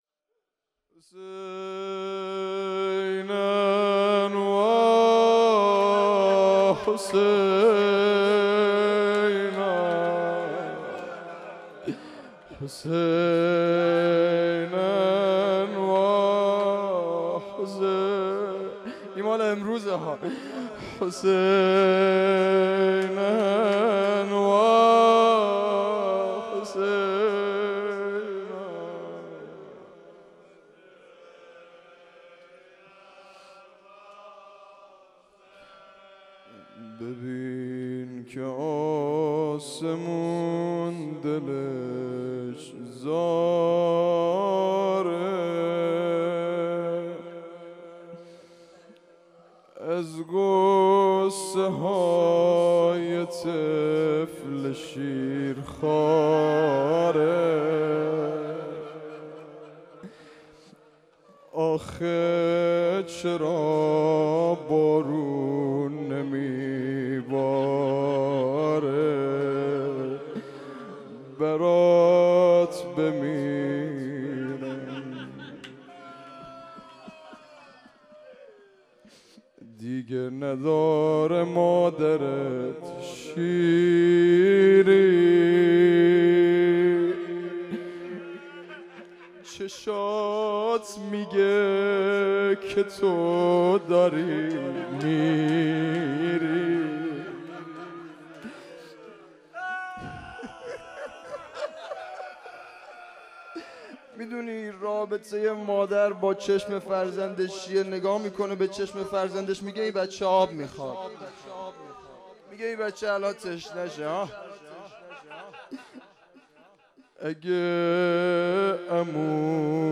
هیئت حسن جان(ع) اهواز
1 0 روضه | روز هفتم دهه اول محرم